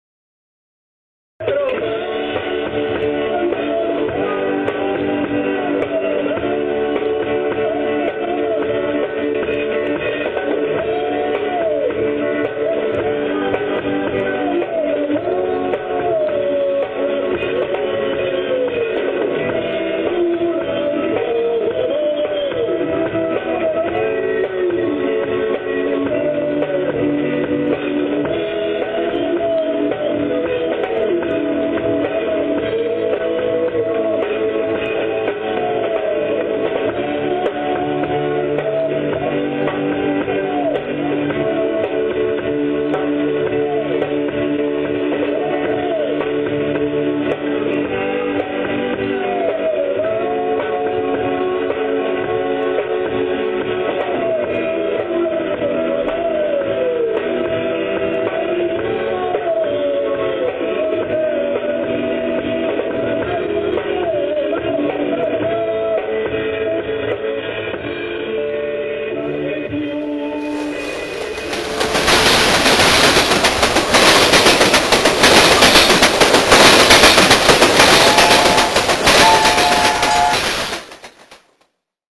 alcuni brani per il loro nuovo doppio CD, una locomotiva impazzita uscì dai binari deragliando terribilmente dentro allo studio.